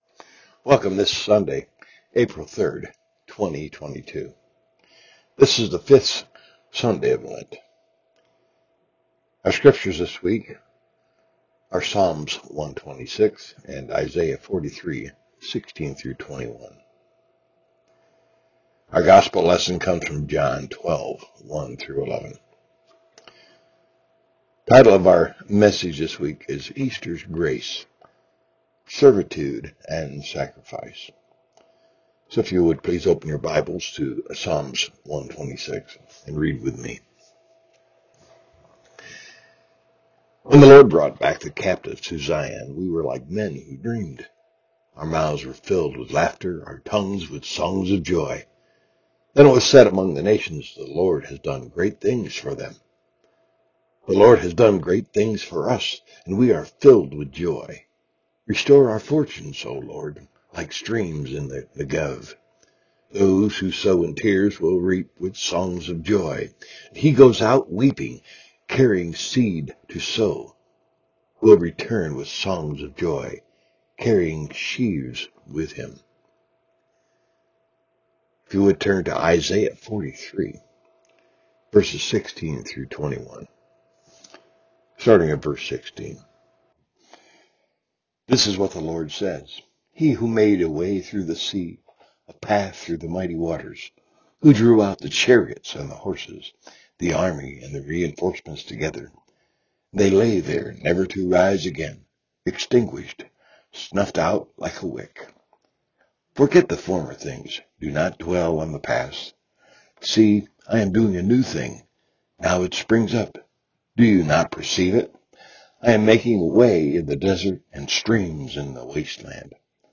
Worship Service – 4/3/2022 « Franklin Hill Presbyterian Church